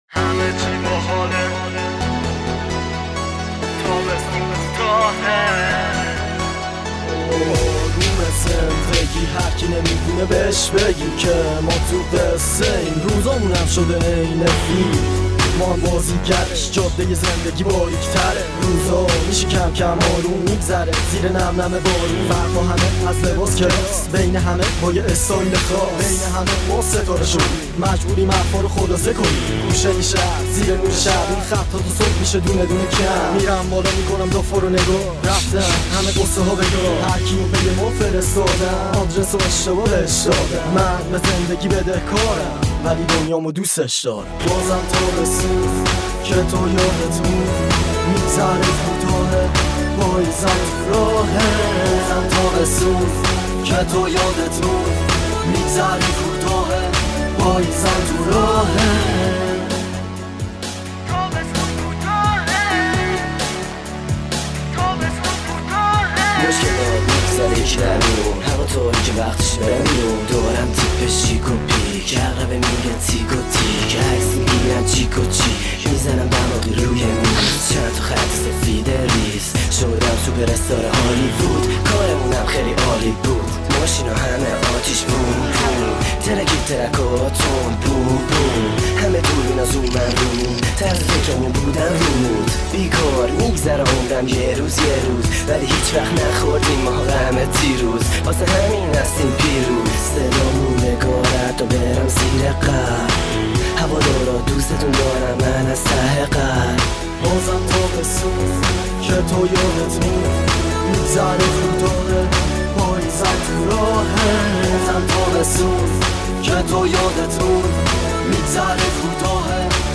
متن این آهنگ رپ :